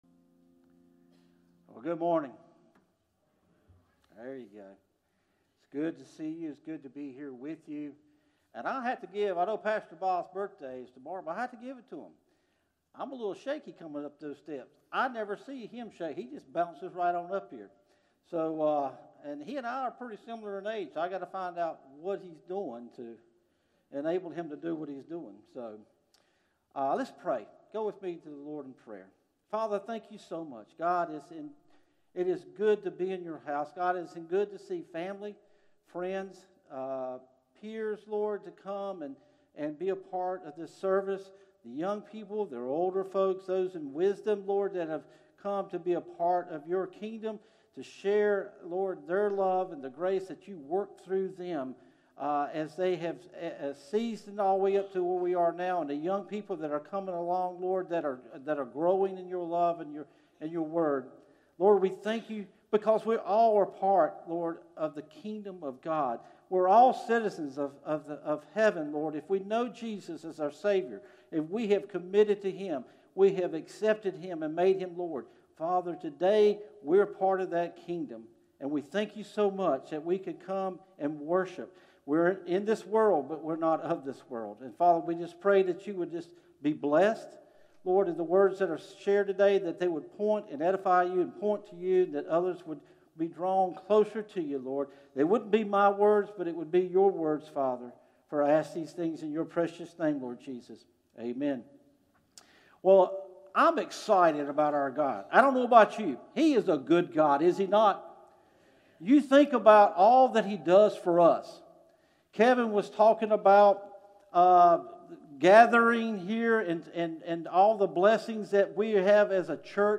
From Series: "Morning Worship - 11am"